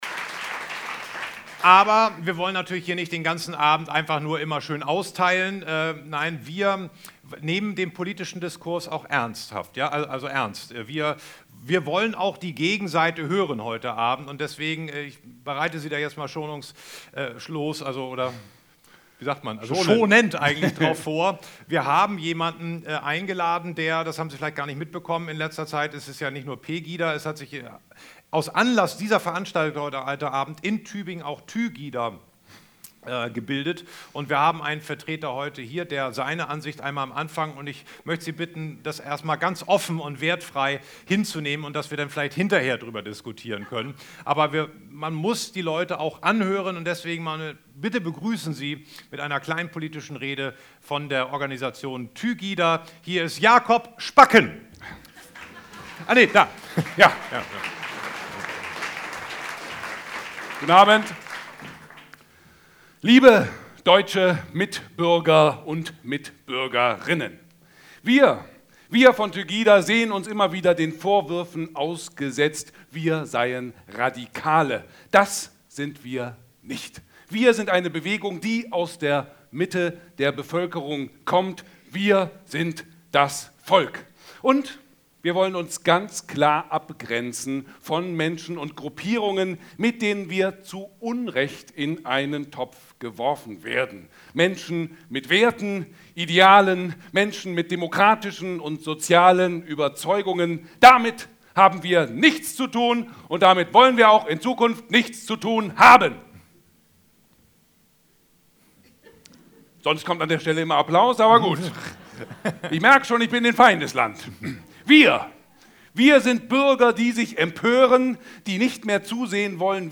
Events, Live-Übertragungen
Es wurde musiziert, Geschichten vorgelesen und Gedichte vorgetragen.
BENEFIZ-COMEDY-MIXED-SHOW